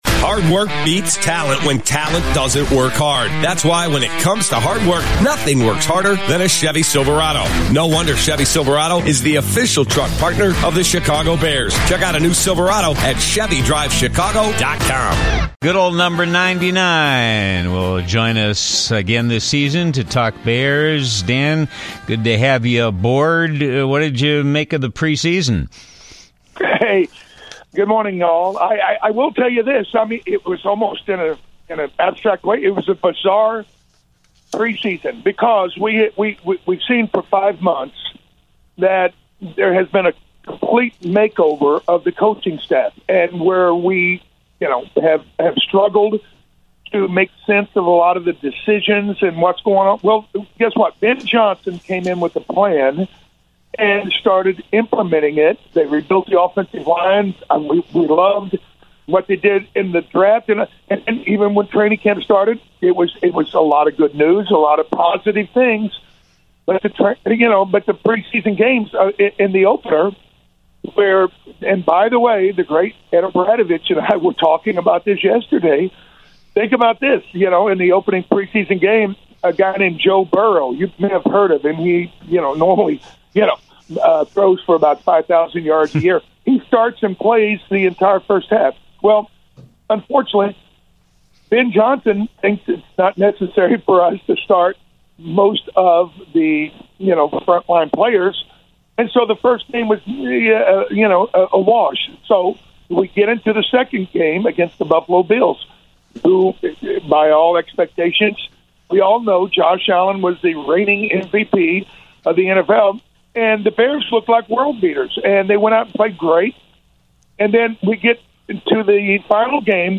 Dan Hampton and Ed O’Bradovich recap the week's Chicago Bears game on WGN Radio 720 in Chicago